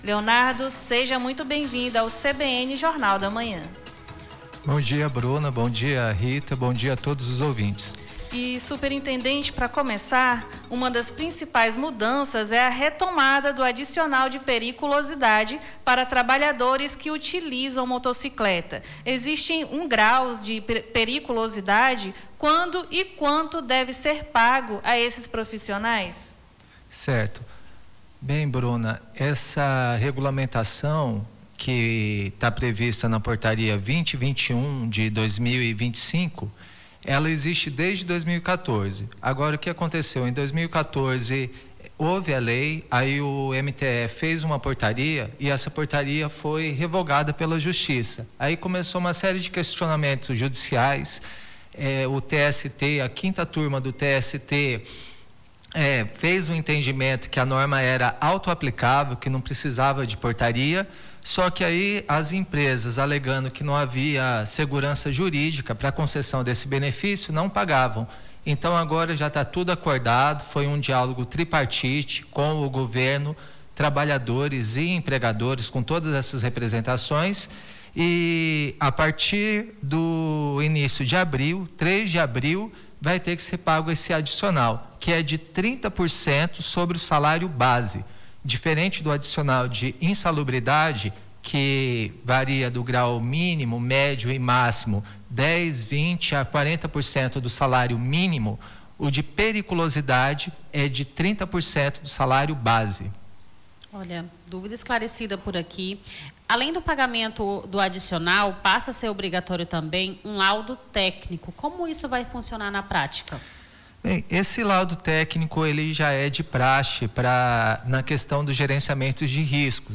Na manhã desta segunda-feira, 23, conversamos com o superintendente Regional do Trabalho, Leonardo Lani, que falou sobre as novas regras da CLT para as empresas.
ENTREVISTA NOVAS REGRAS CLT